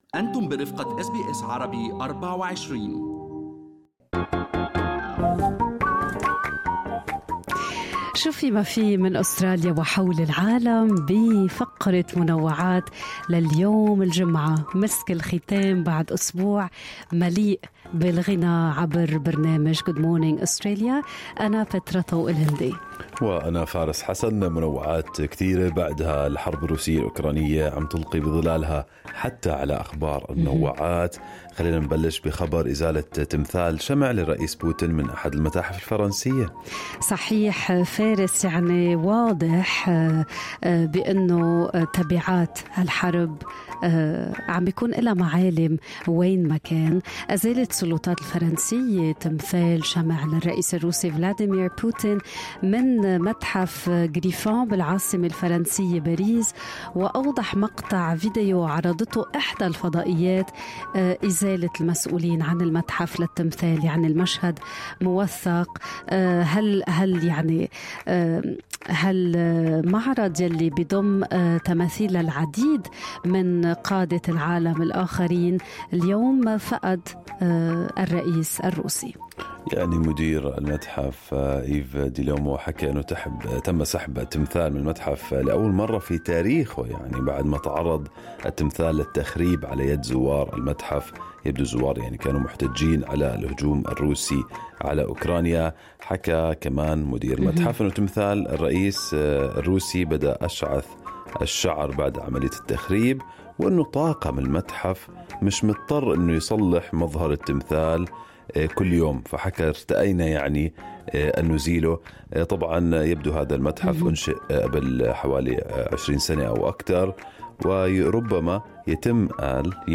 نقدم لكم فقرة المنوعات من برنامج Good Morning Australia التي تحمل إليكم بعض الأخبار والمواضيع الخفيفة.